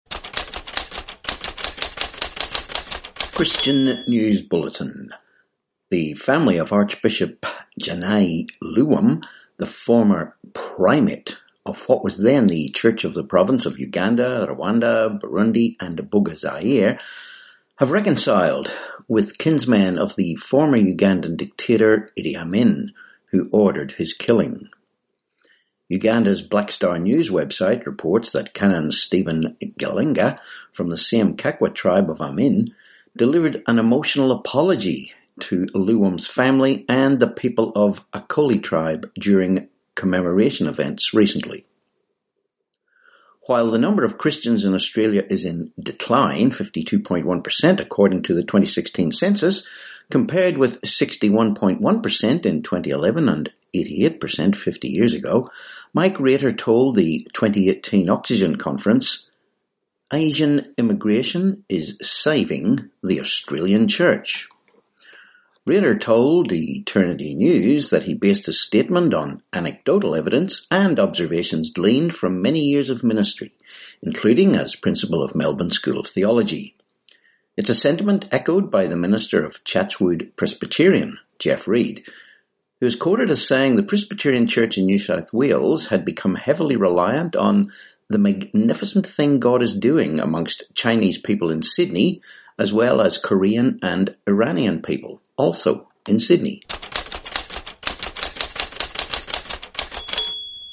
14Apr19 Christian News Bulletin